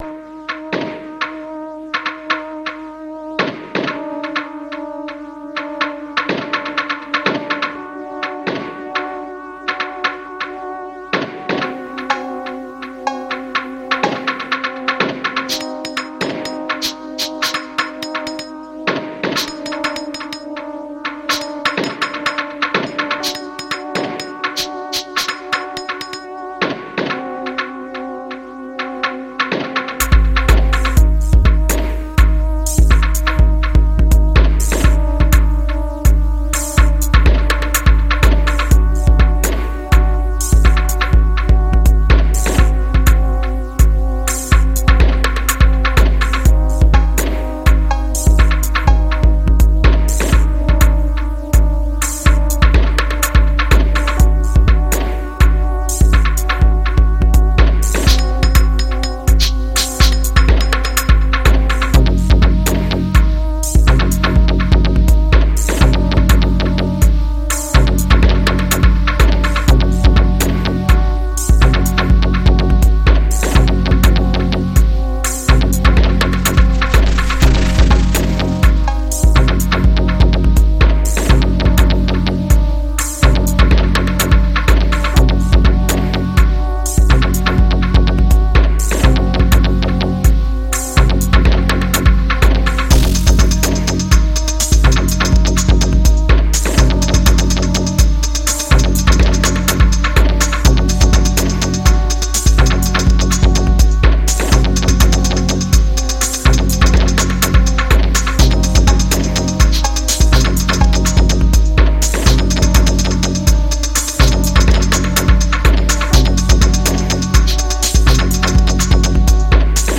Fresh braindance house tracks... sort of.